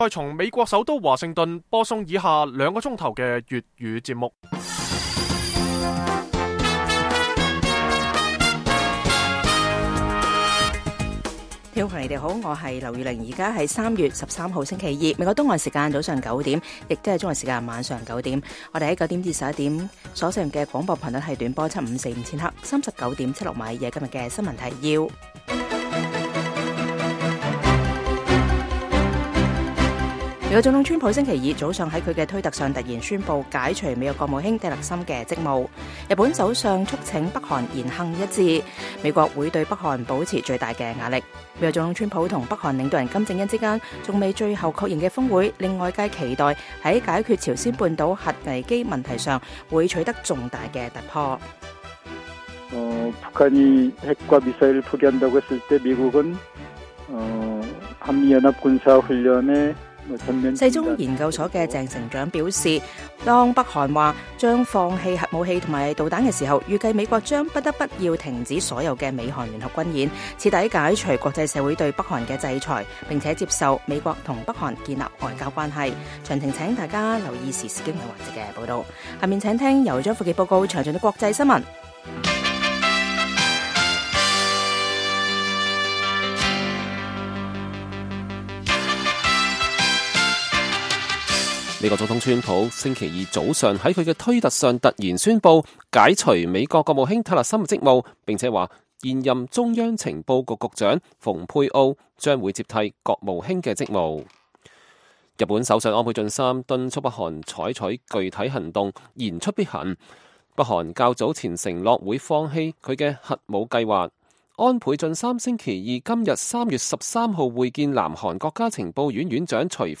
粵語新聞 晚上9-10點
北京時間每晚9－10點 (1300-1400 UTC)粵語廣播節目。